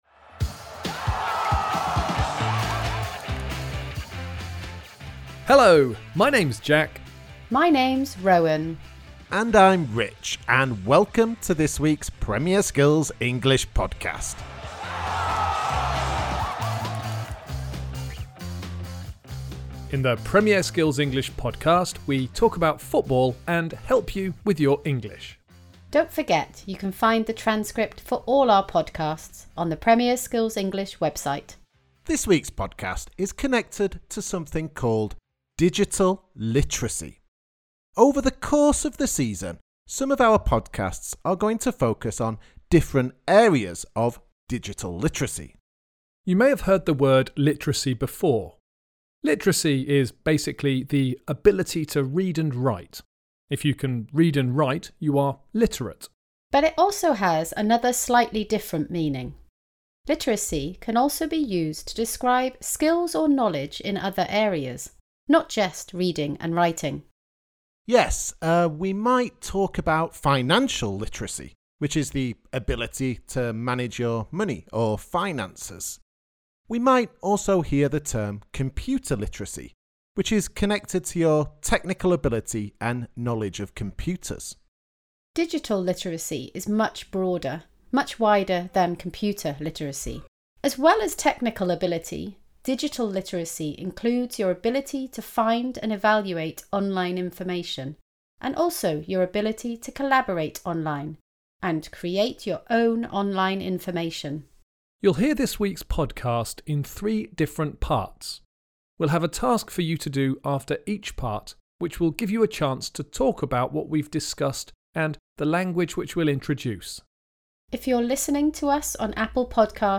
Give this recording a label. Skills: Listening